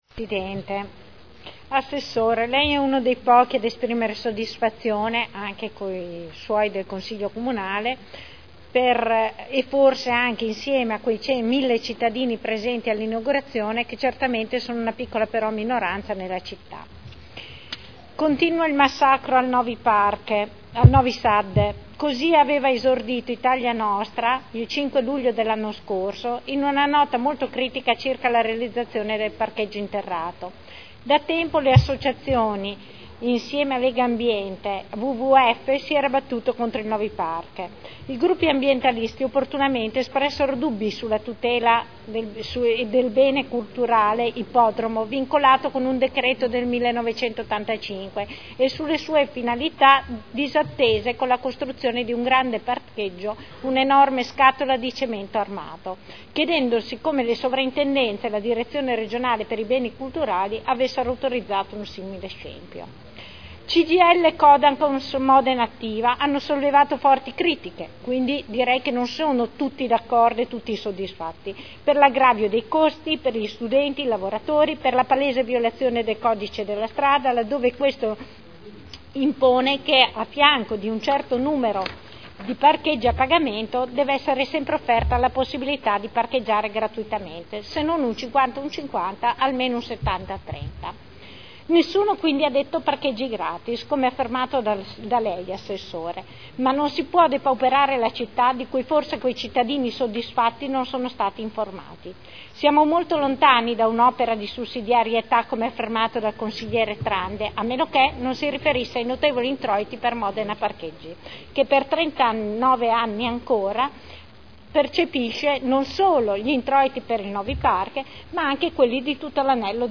Dibattito su interrogazioni presentate dai Consiglieri Santoro e Morandi sul "piano sosta" trasformate in interpellanza su richiesta del Consigliere Barcaiuolo, del Consigliere Galli e del Consigliere Santoro.